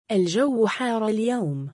Pronunciation : al-jaww ḥār al-yawm